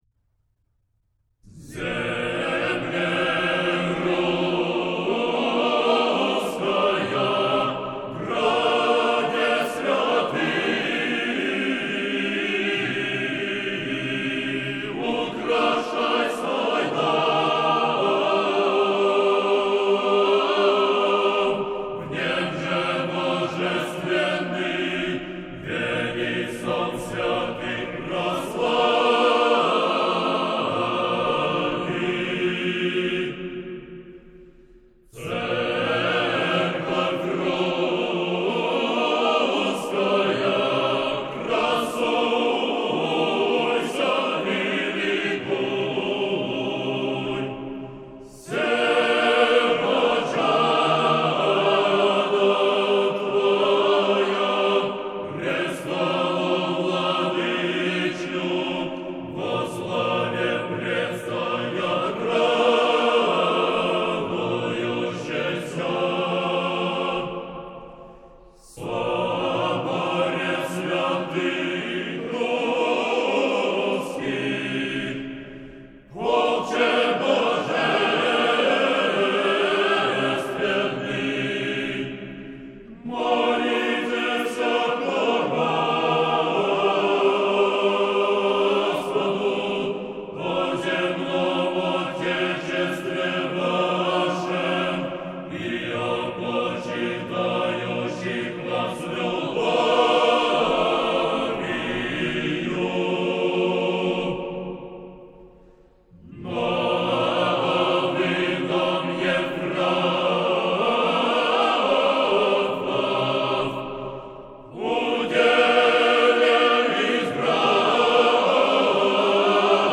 Хор Киево-Печерской Лавры. Стихира "Земле Русская" (музыкальные файлы)